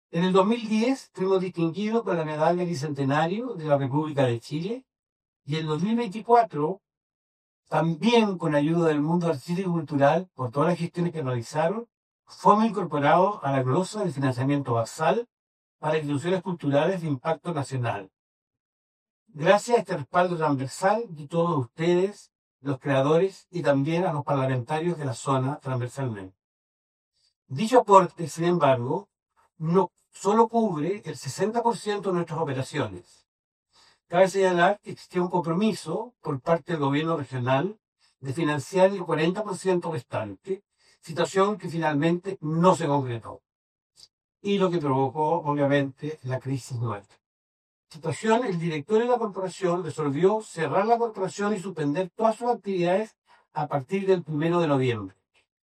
Este miércoles 29 de octubre de 2025 se realizó una conferencia de prensa en la sede de la Corporación Artistas del Acero, instancia en la que se informó oficialmente el cierre de la institución.